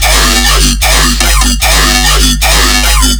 TOOGGOOT_bass_loop_yoy_mambo_3_150_F.wav